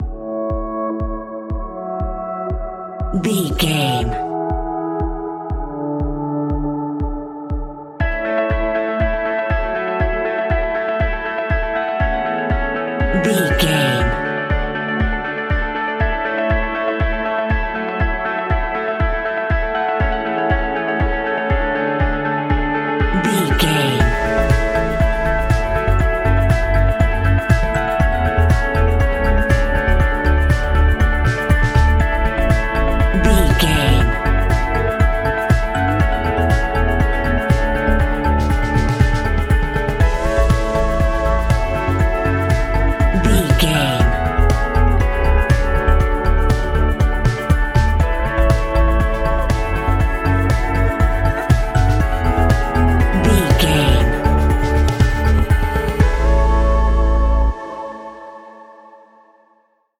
Ionian/Major
energetic
uplifting
bass guitar
electric guitar
synthesiser
percussion
sleigh bells
drums